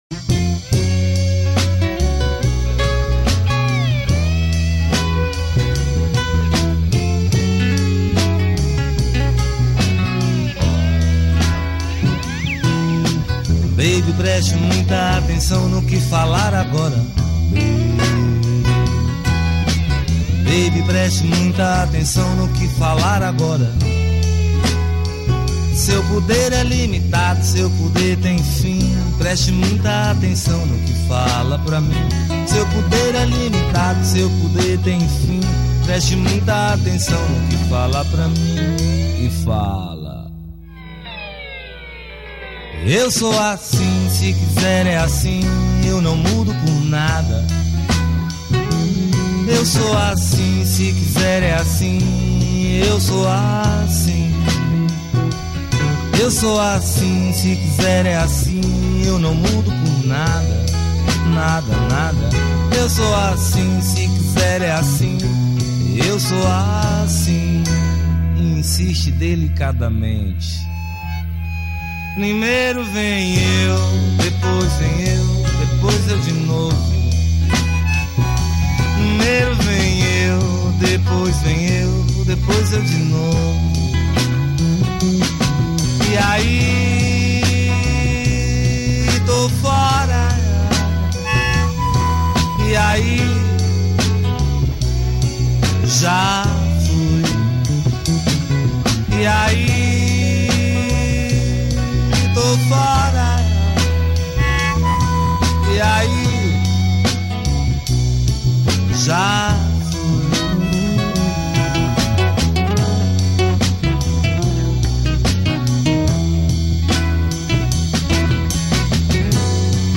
2155   02:47:00   Faixa:     Reggae